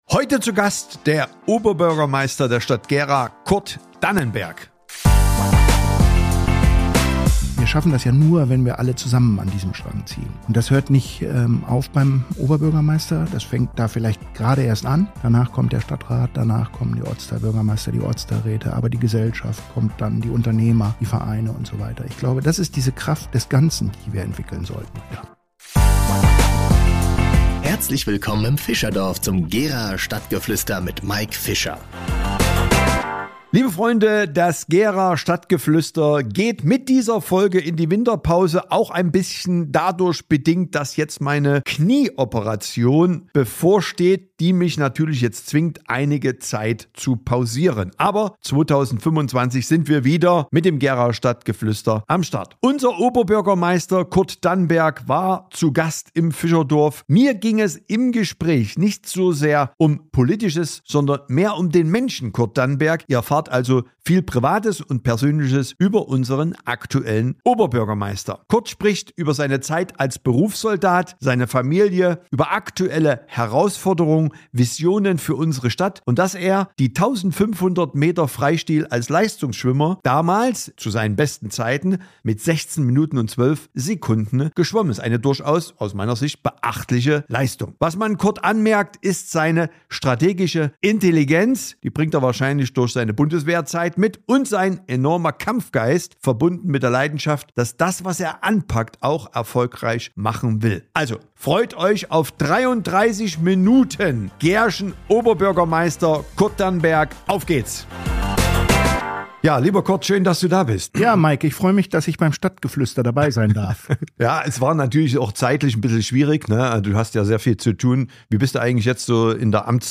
Unser Oberbürgermeister Kurt Dannenberg war zu Gast im FischerDorf.Mir ging es im Gespräch weniger um die Politik, sondern vielmehr um den Menschen Kurt Dannenberg. Ihr erfahrt viel Persönliches über unseren aktuellen Oberbürgermeister.